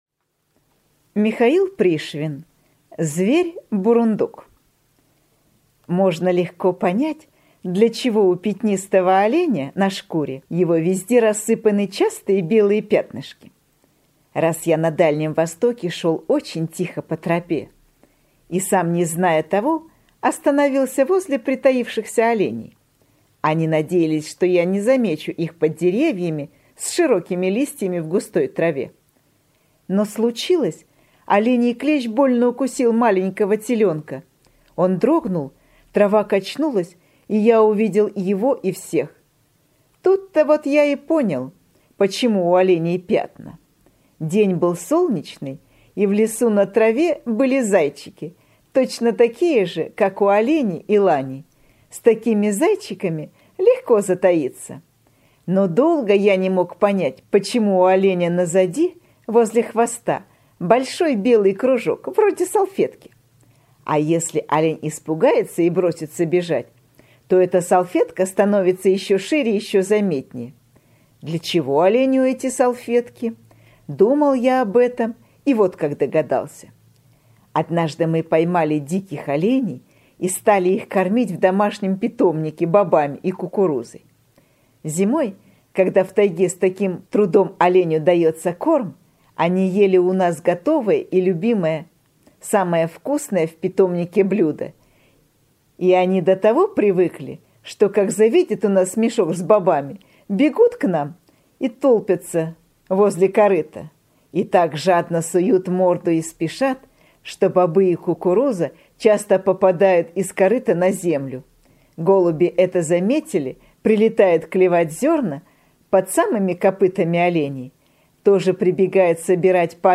Зверь бурундук – Пришвин М.М. (аудиоверсия)
Аудиокнига в разделах